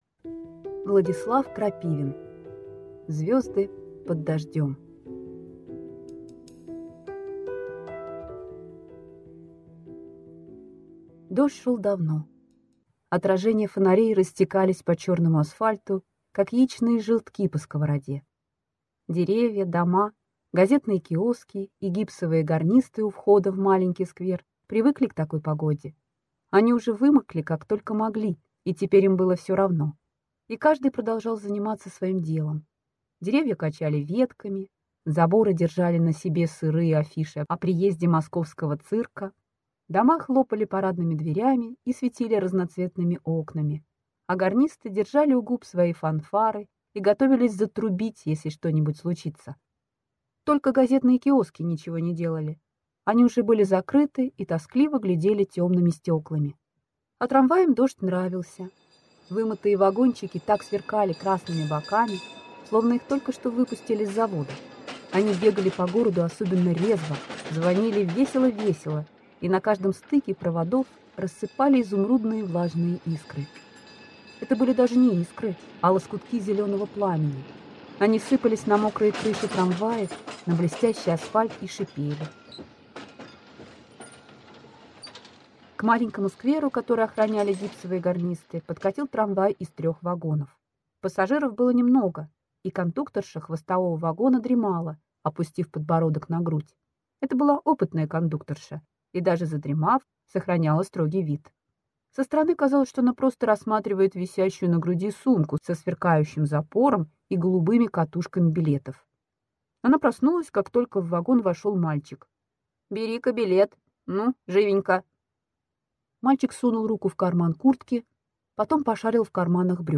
Аудиокнига Звезды под дождем | Библиотека аудиокниг